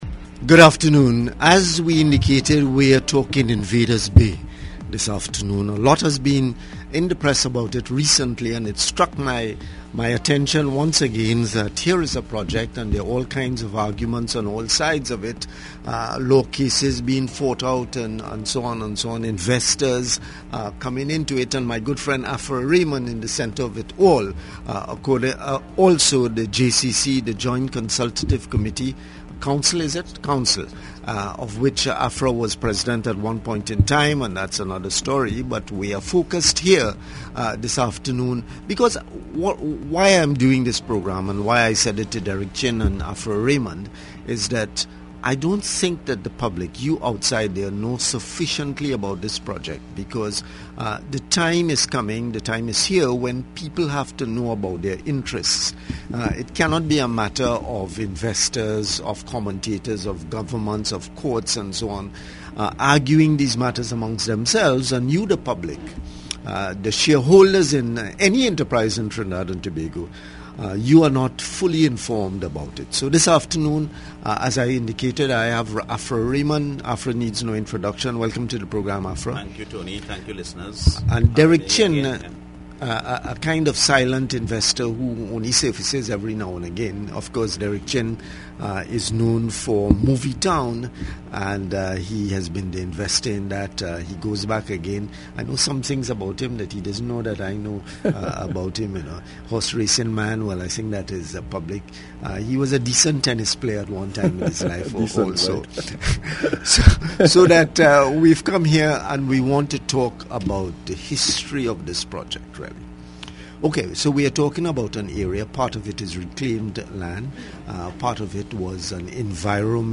AUDIO: The Breakfast Round Table interview on Sky 99.5FM – 10 Aug 2015